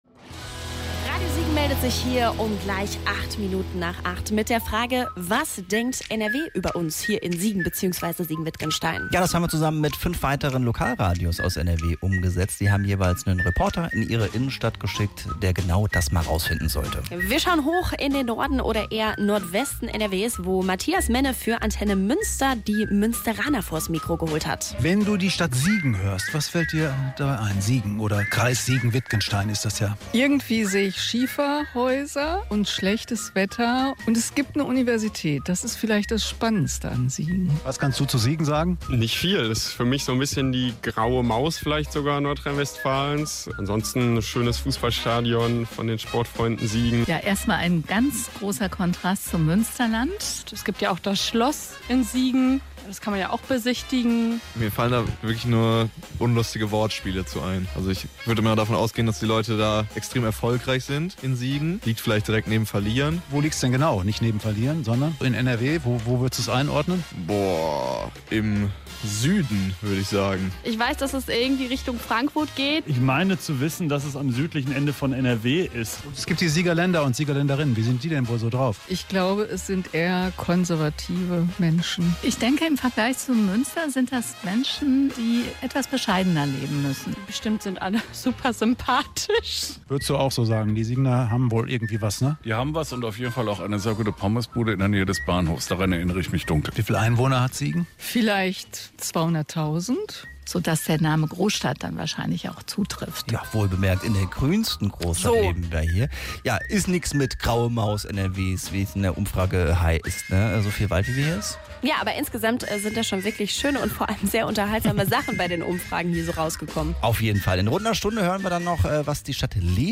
Mit Straßenumfragen aus Köln, Dortmund, Essen, Münster und Leverkusen.